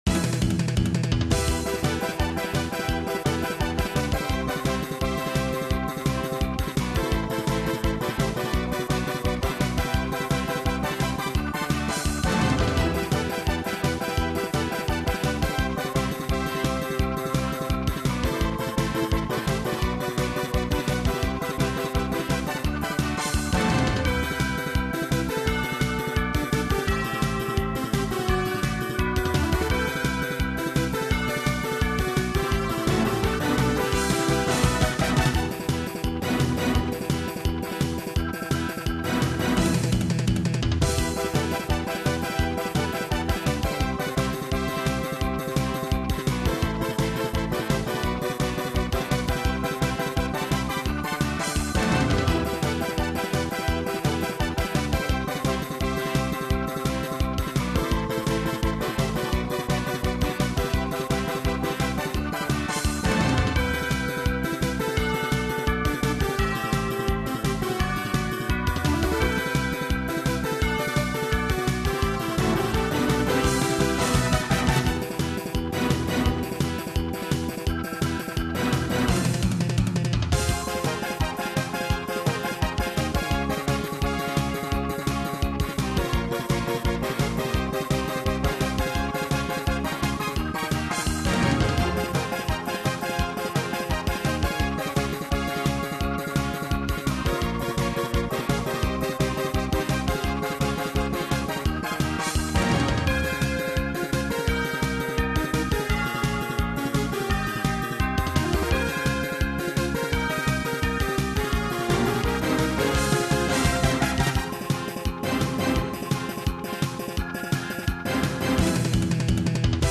Bien kitsch.